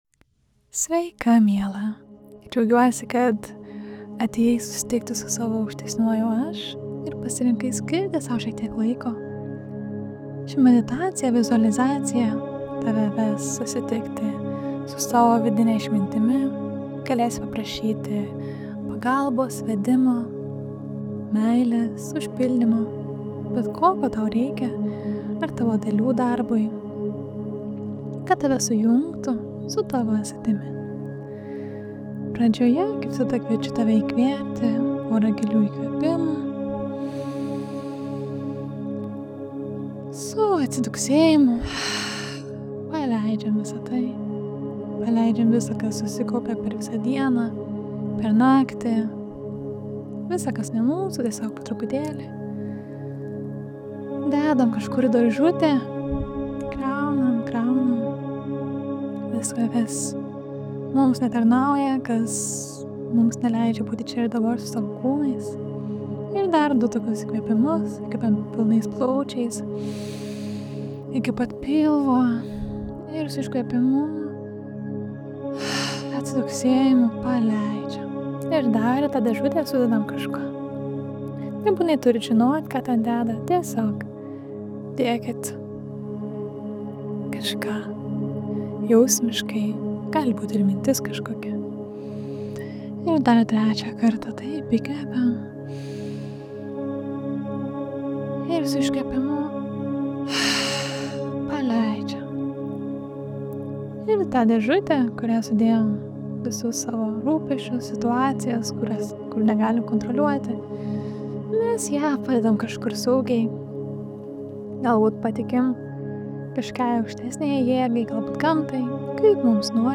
Meditacija lietuviskai parsisiusti
meditacija-susijungti-su-auksitesniuoju-asi-AVL78BBBE0i9DEkj.mp3